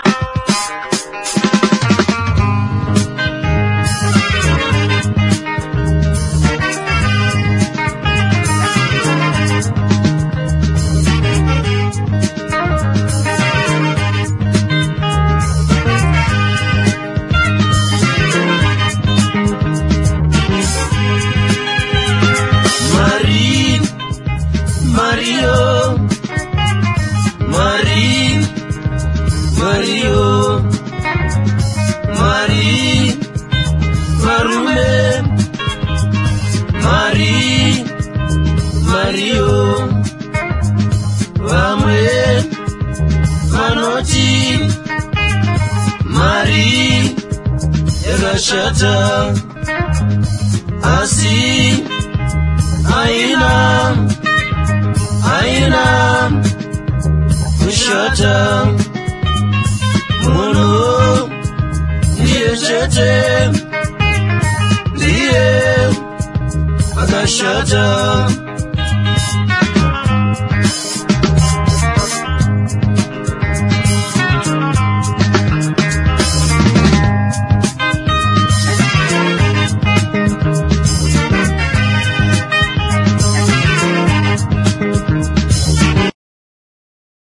WORLD / OTHER / AFRICA / ZIMBABWE
トロピカルなジンバブエ・コンピ！